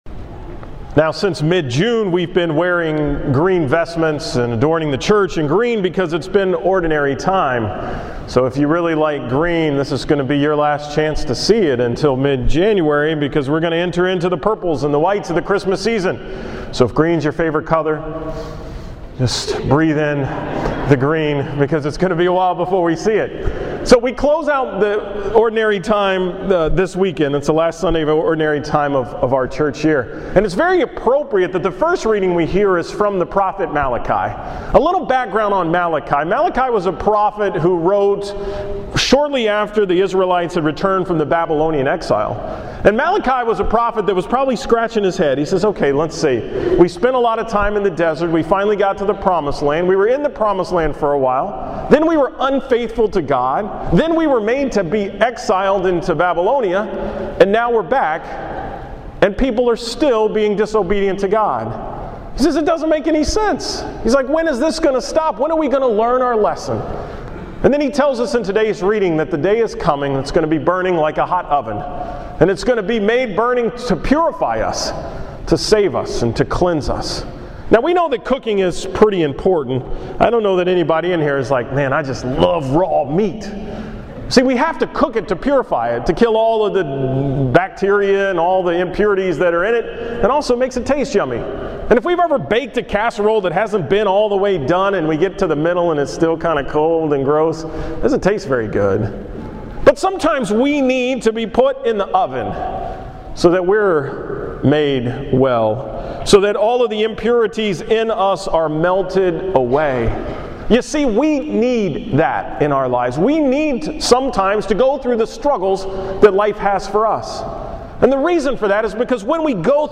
From the 9 am Mass on Sunday, November 17.